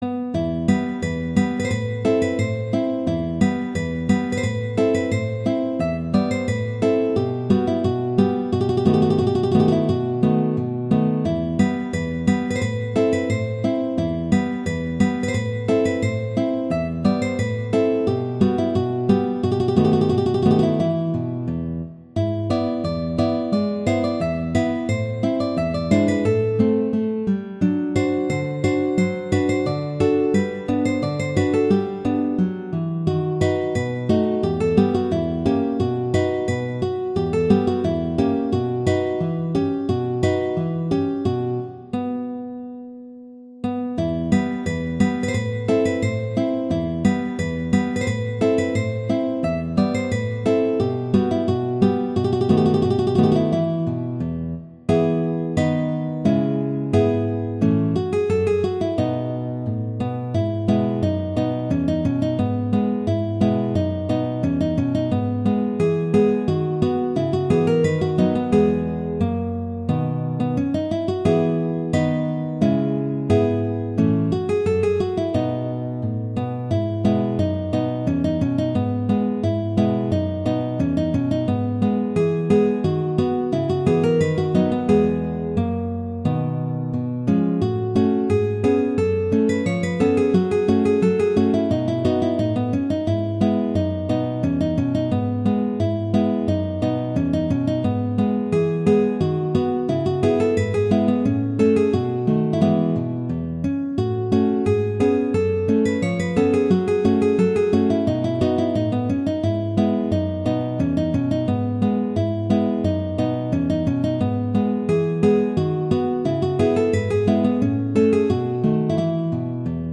classical, french, wedding, festival, love
E minor
♩=176 BPM